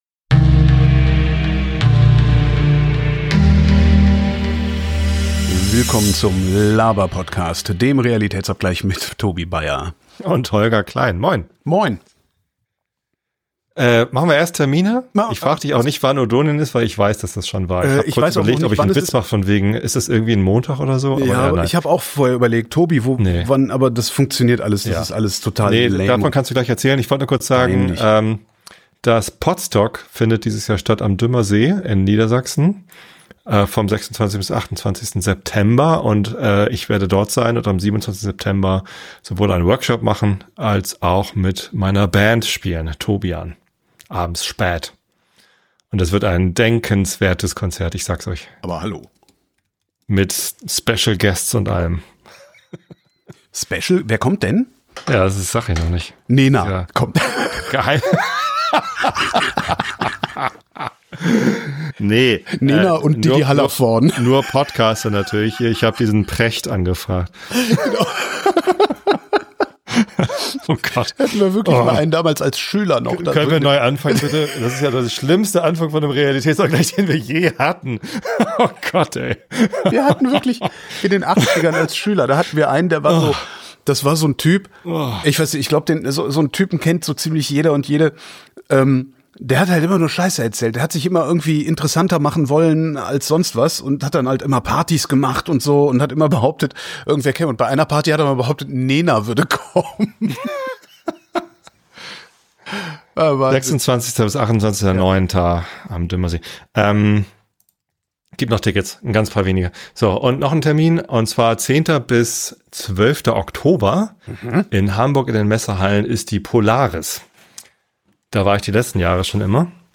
Der Laberpodcast.